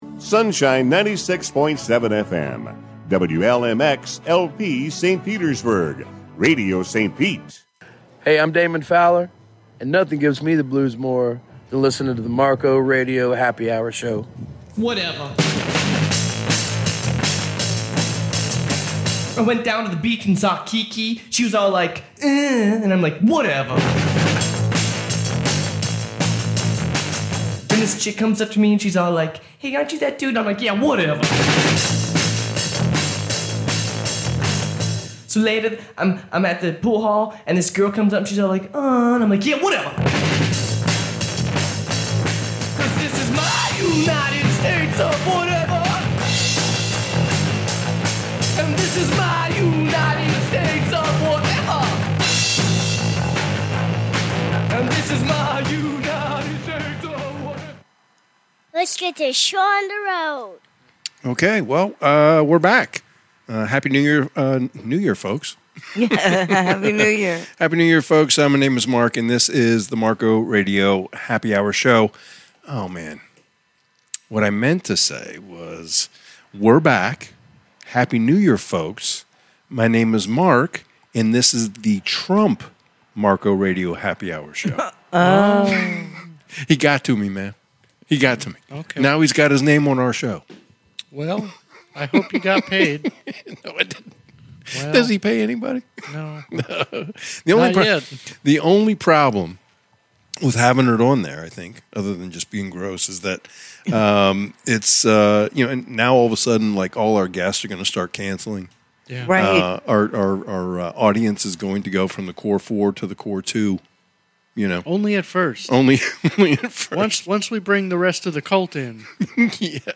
live postgame show